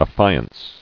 [af·fi·ance]